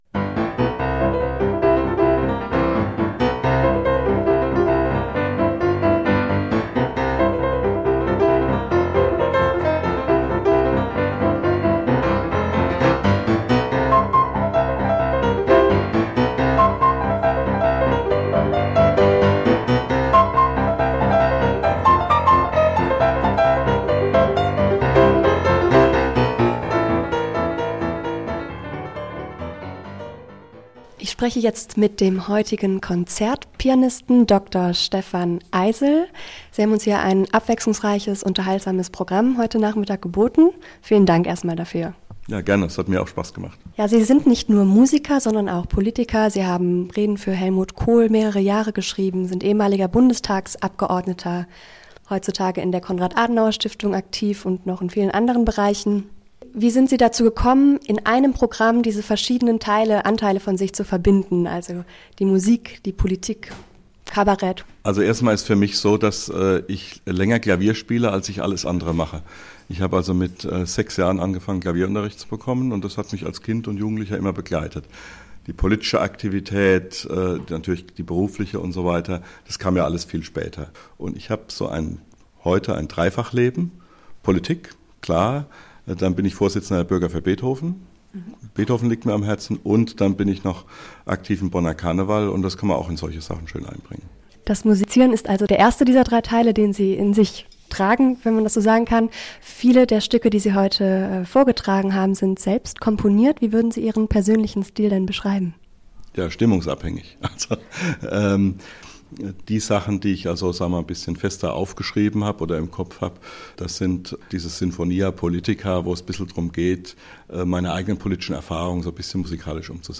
Hier klicken zum Interview "Musik und Politik"